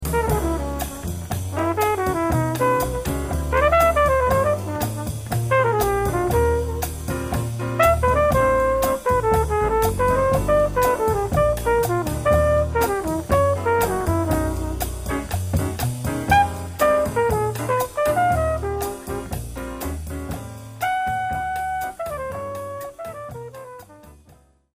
An airy bossa with constantly shifting harmonic movement.